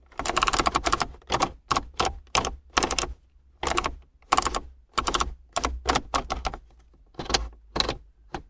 Звуки регулятора громкости
Звук: многооборотный резистор с щелчком при увеличении громкости